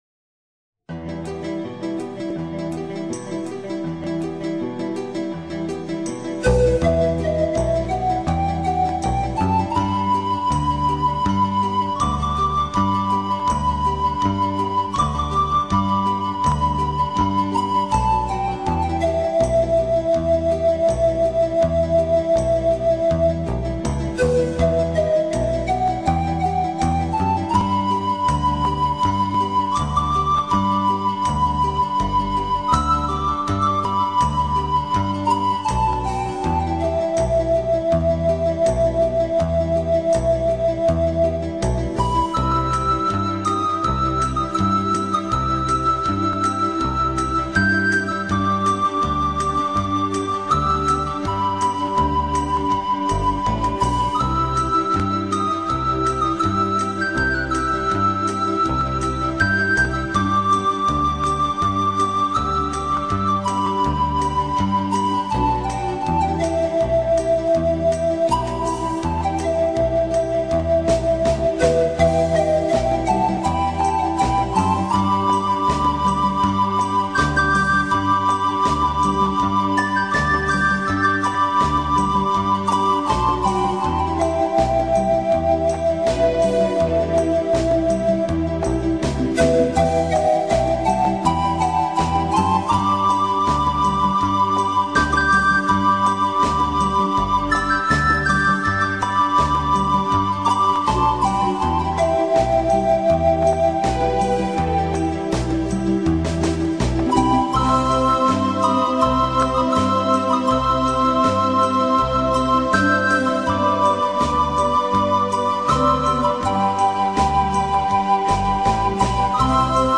KGntNxbmLfw_El-cóndor-pasa-instrumental-.m4a